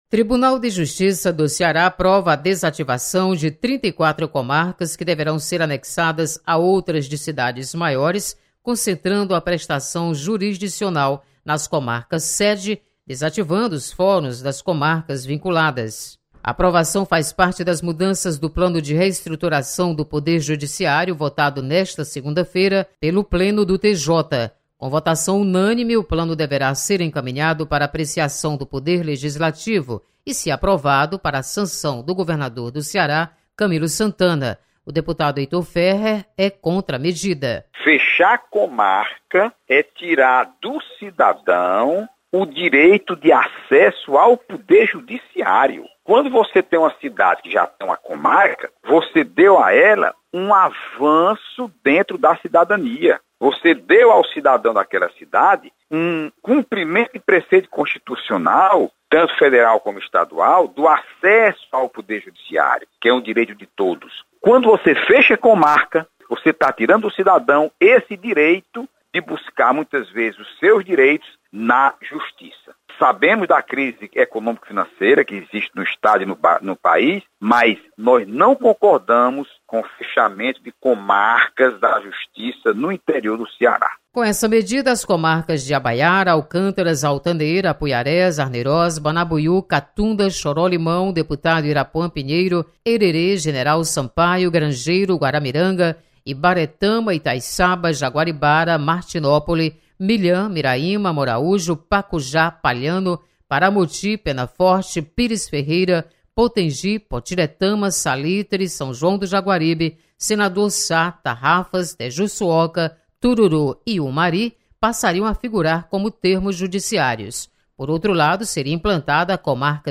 Deputados comentam sobre desativação de comarcas. Repórter